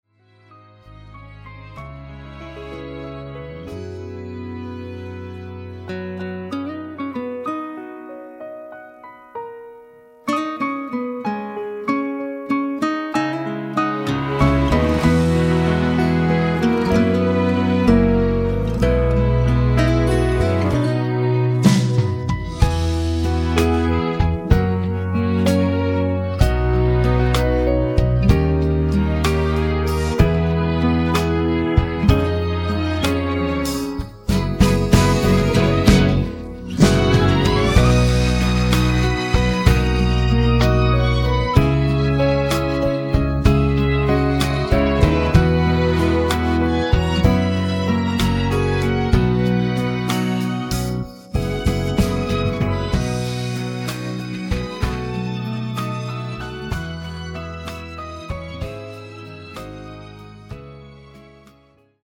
음정 원키 4:03
장르 가요 구분 Voice Cut